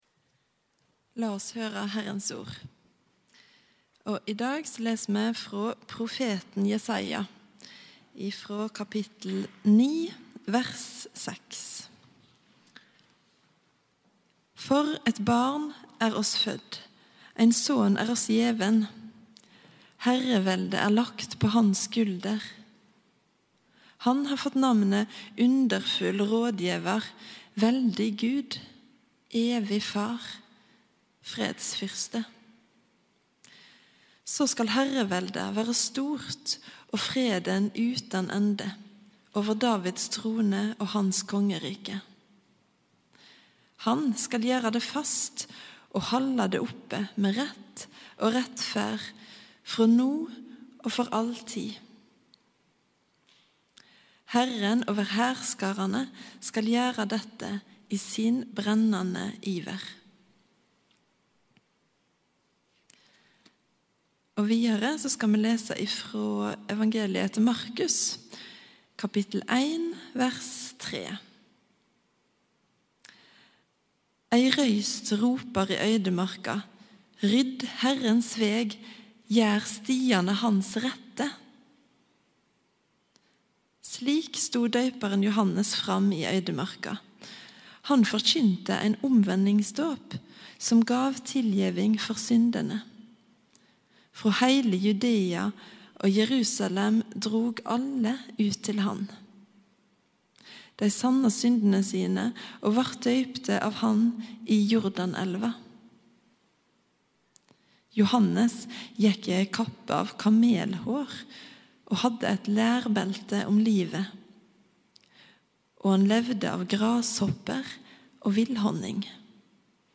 Gudstjeneste 10. januar 2021, -Jesus Er | Storsalen
Velkommen-til-gudstjeneste-10.-januar-1.mp3